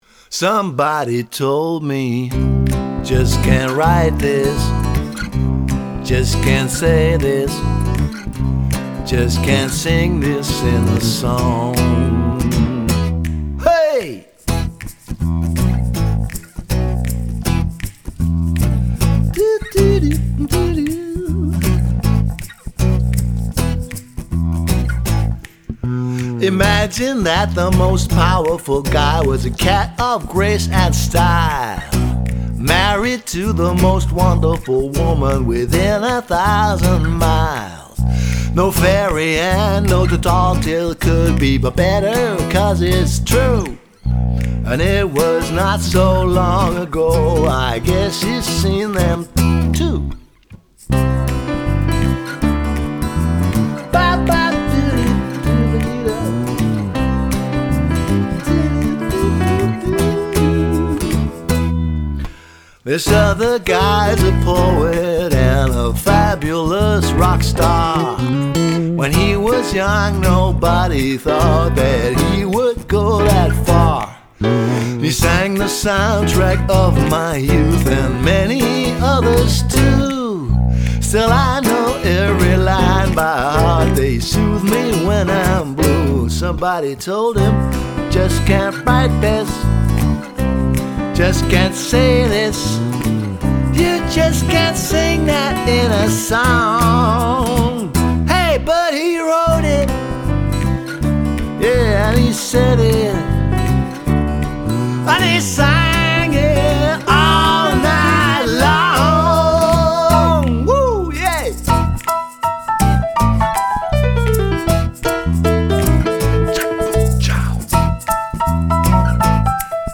Rock `n´ Roll & Seemannsgarn/ Free Music & Tall Tales